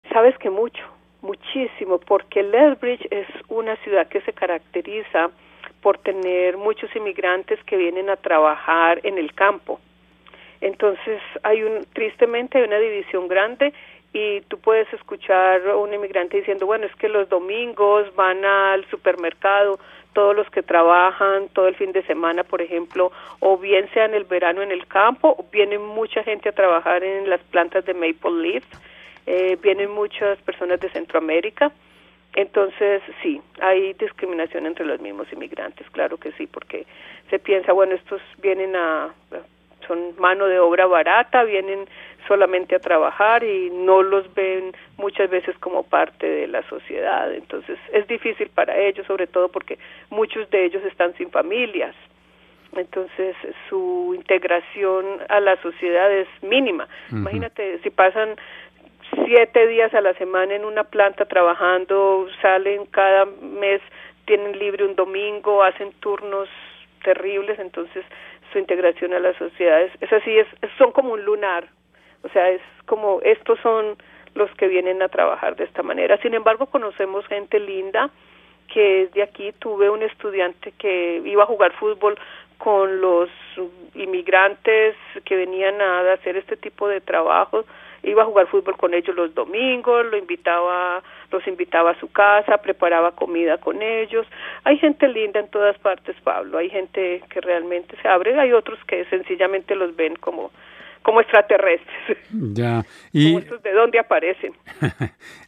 En términos generales, ella estaba satisfecha, pero no conforme, nos dice en entrevista.